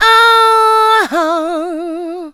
SCREAM 4.wav